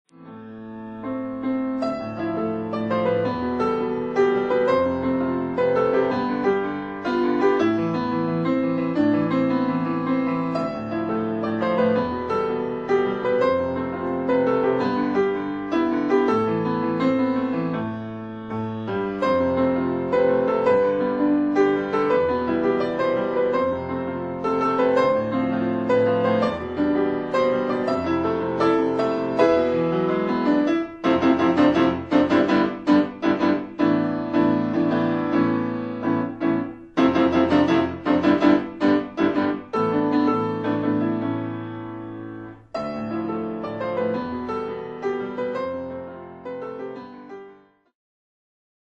音楽ファイルは WMA 32 Kbps モノラルです。
Piano